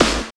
20 SNARE 2-R.wav